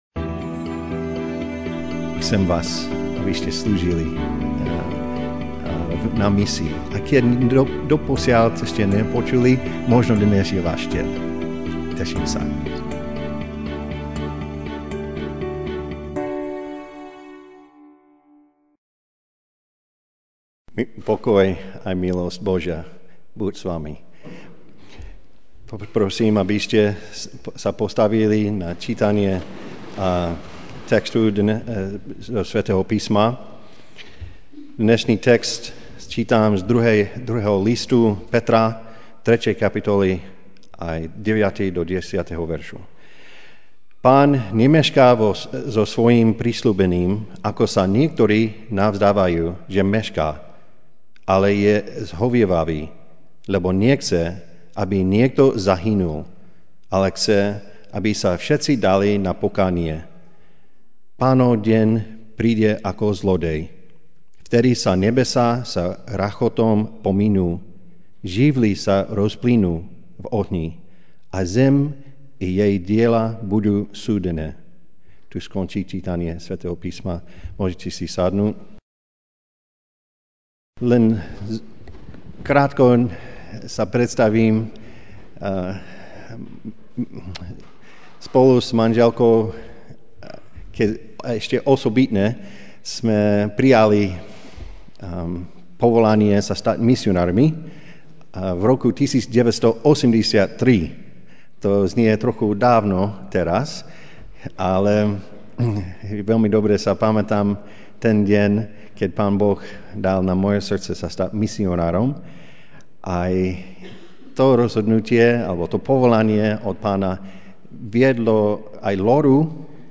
Ranná kázeň: Druhá motivácia celosvetovej misie (2. Pt. 3, 9-10) Pán nemešká so zasľúbením, ako sa niektorí nazdávajú, že mešká, ale vám zhovieva, lebo nechce, aby niekto zahynul, ale aby sa všetci dali na pokánie.